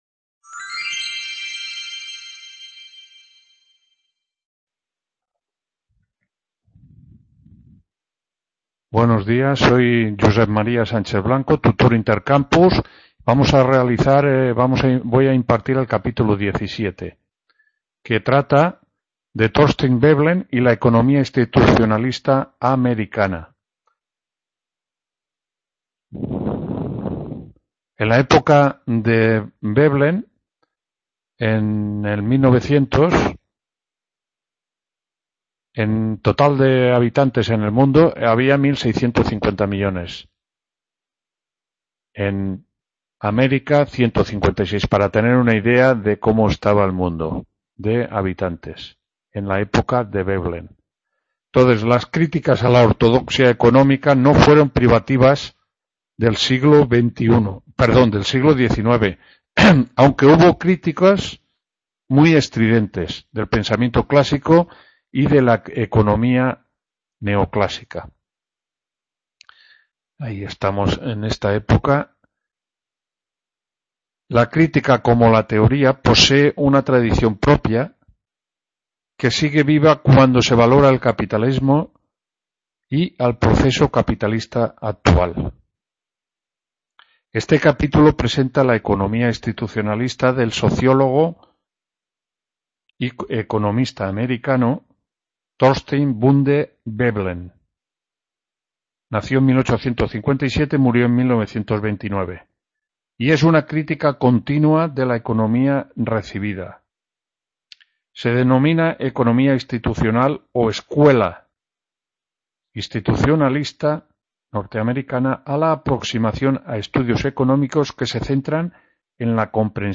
4ª (I) TUTORÍA-CAP 17 VEBLEN HISTORIA DEL PENSAMIENTO… | Repositorio Digital